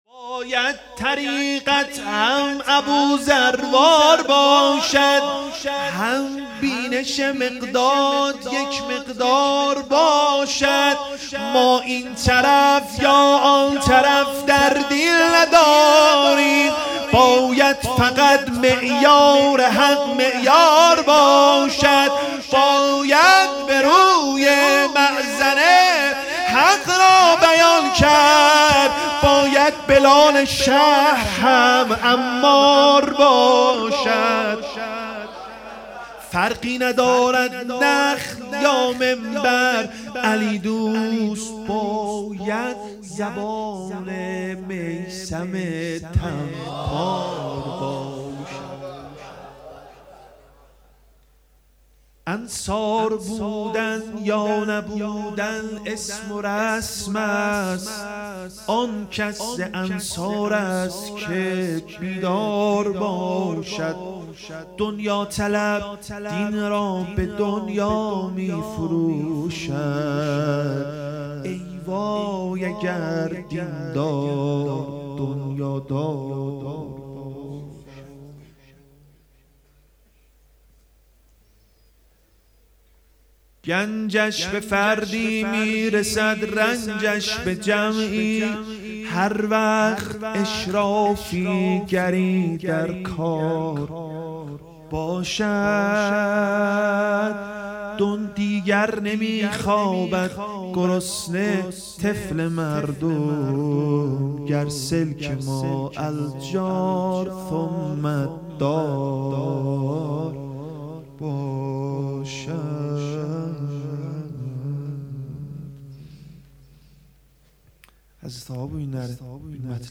شعر خوانی حماسی
ولادت حضرت زهرا (س) 1402